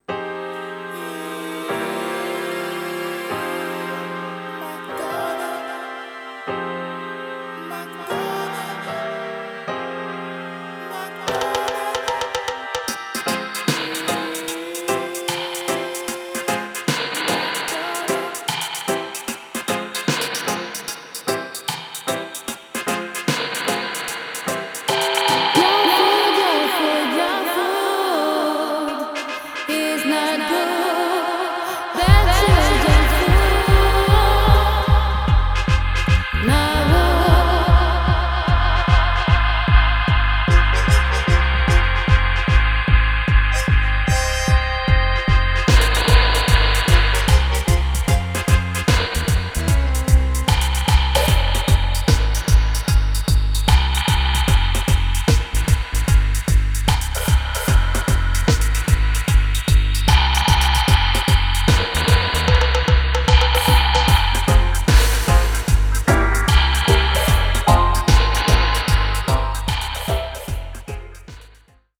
Space Dub Mix
Vocal Dub Mix
Organ